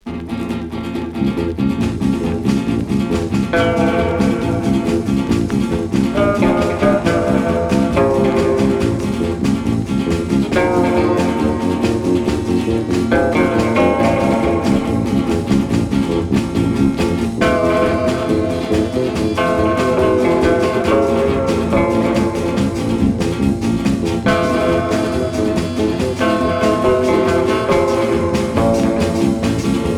Rock instrumental Unique EP retour à l'accueil